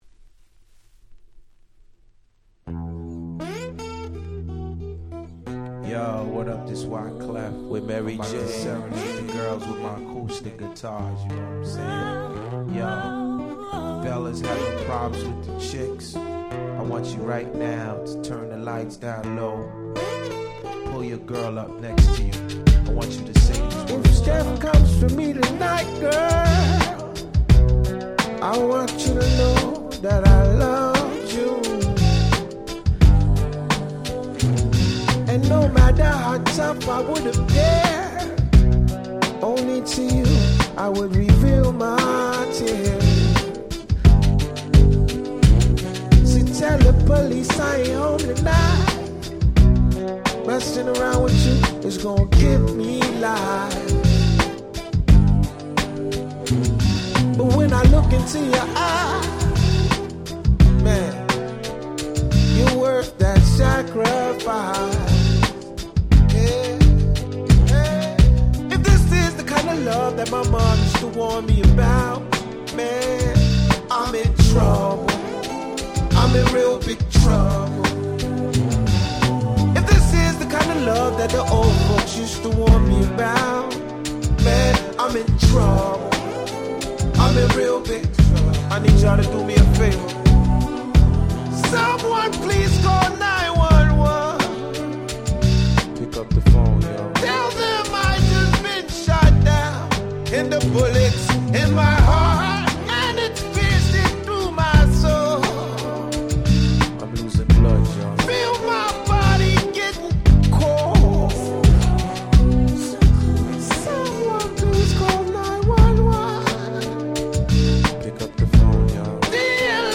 00' Super Hit R&B / Hip Hop !!